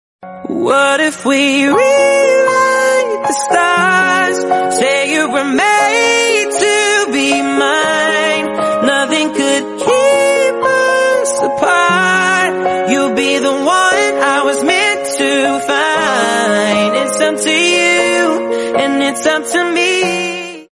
crying emoji😭 sound effects free download